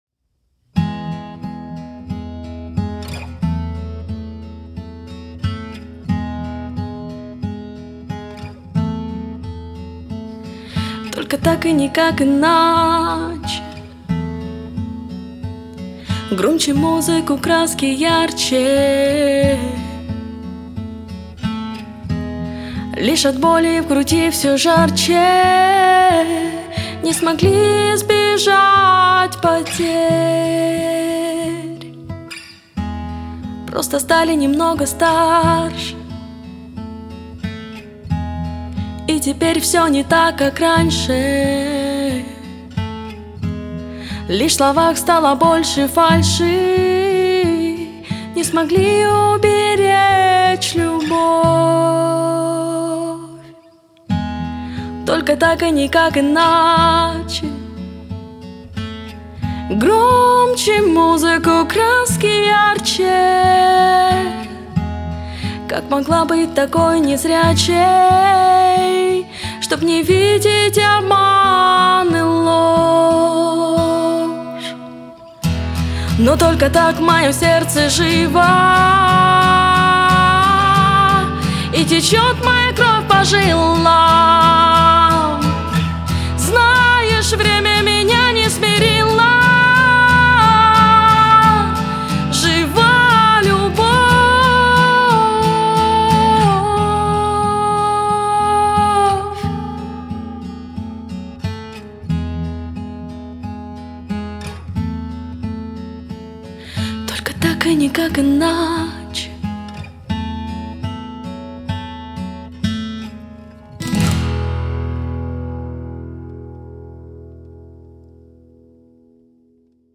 1339 просмотров 288 прослушиваний 34 скачивания BPM: 180